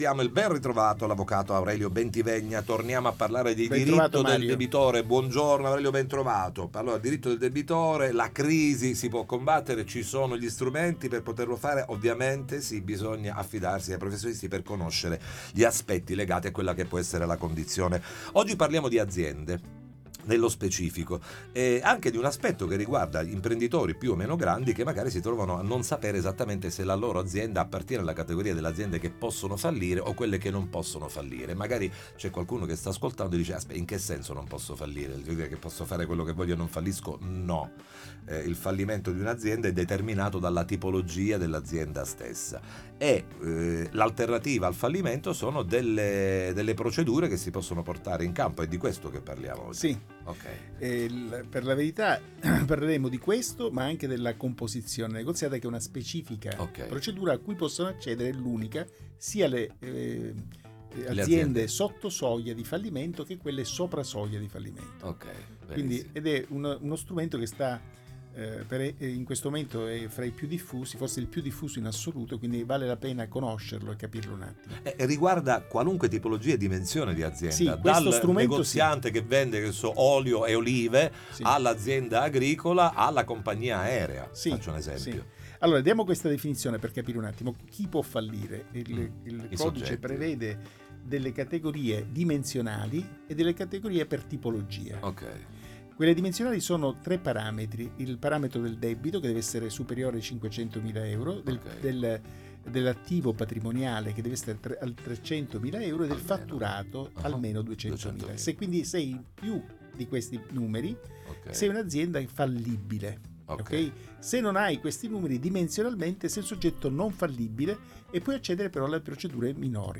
9a Puntata Interviste Time Magazine 09/12/2025 12:00:00 AM